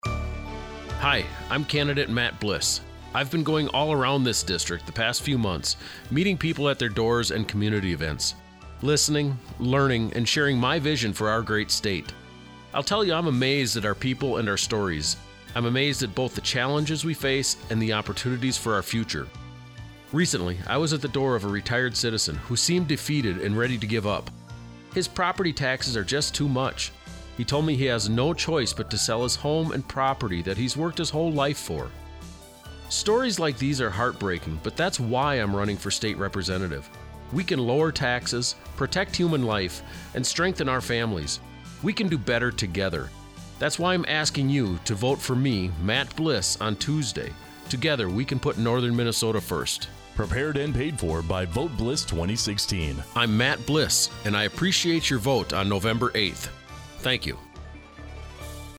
10/31 Radio Ad (Version B)